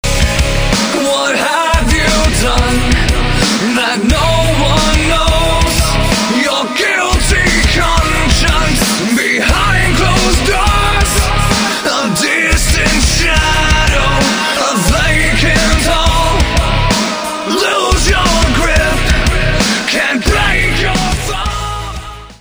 • Качество: 320, Stereo
мужской вокал
громкие
Драйвовые
Hard rock
Рок рингтон